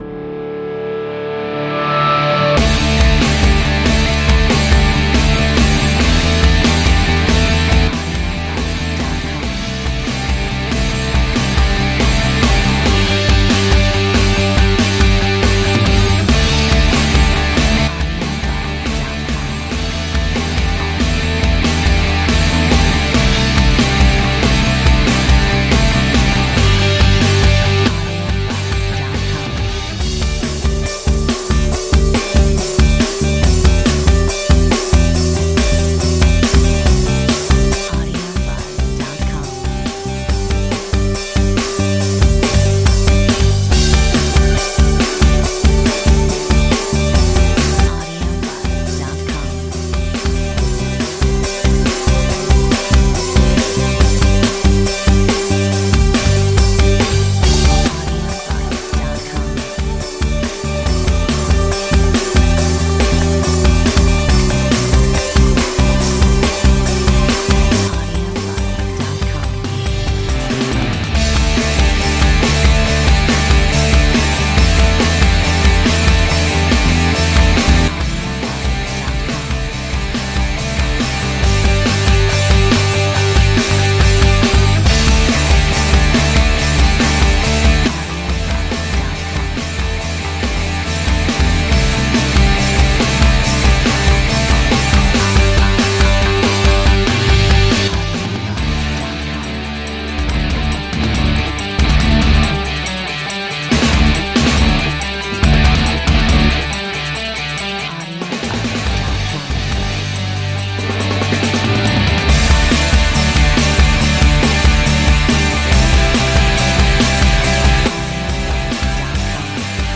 Metronome 140